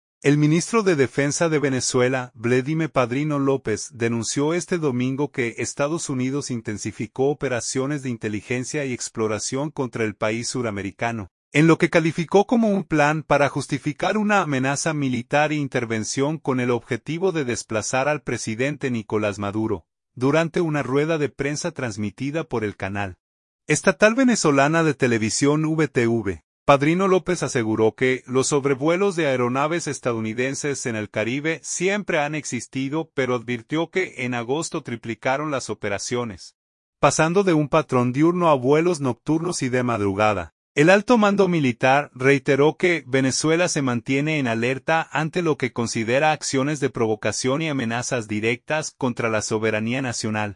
Durante una rueda de prensa transmitida por el canal estatal Venezolana de Televisión (VTV), Padrino López aseguró que los sobrevuelos de aeronaves estadounidenses en el Caribe “siempre han existido”, pero advirtió que en agosto “triplicaron las operaciones, pasando de un patrón diurno a vuelos nocturnos y de madrugada”.